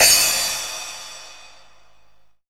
VEC3 Crash